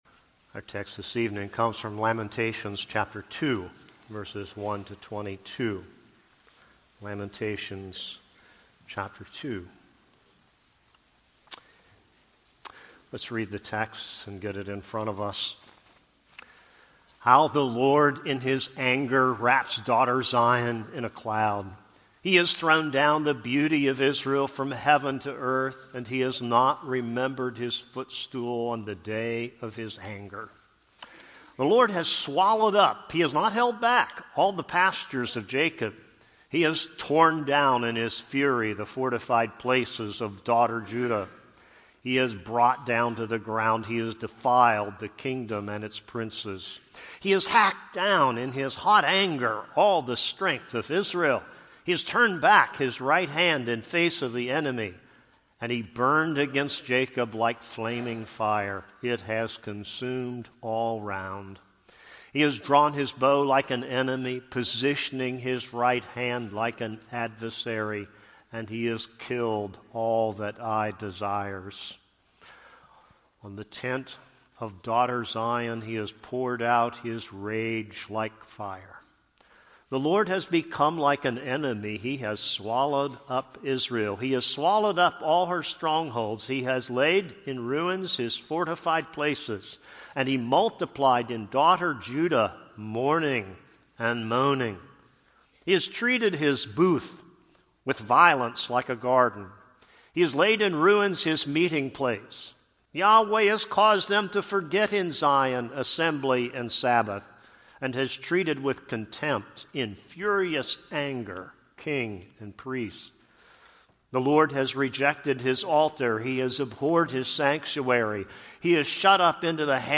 This is a sermon on Lamentations 2.